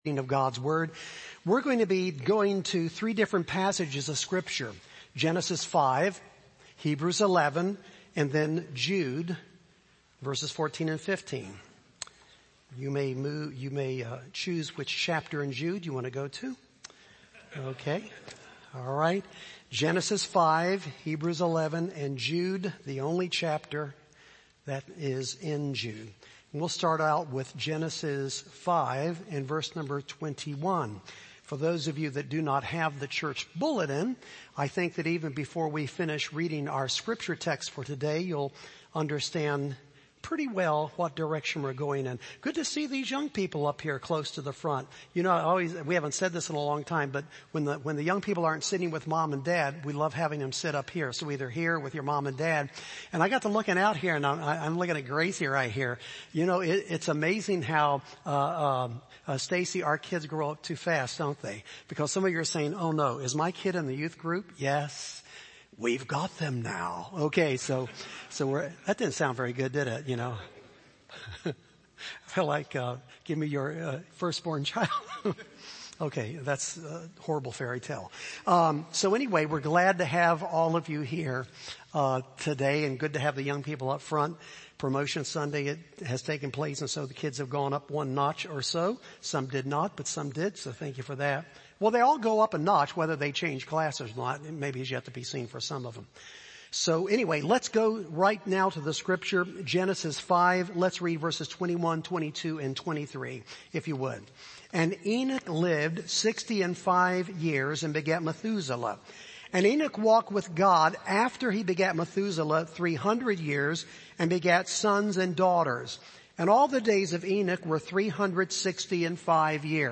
Sermons Podcast - Enoch, The Man Who Walked With God - AM | Free Listening on Podbean App